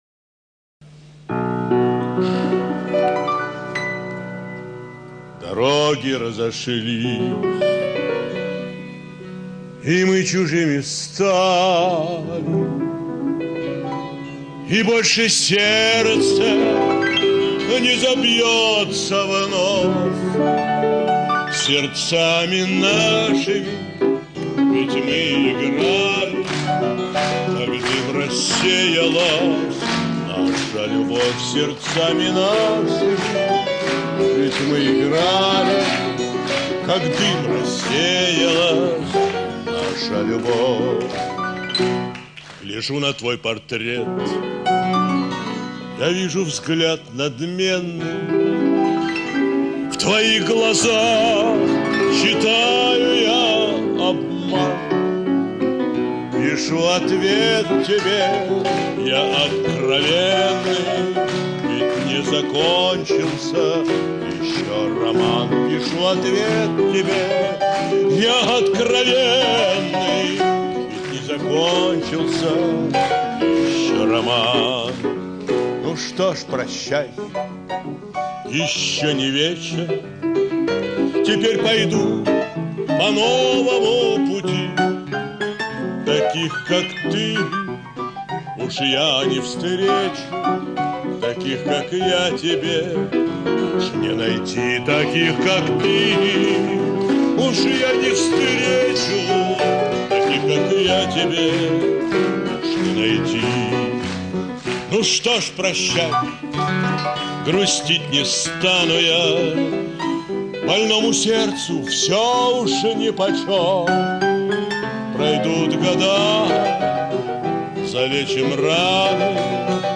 Я попросил доброго человека выделить её из передачи.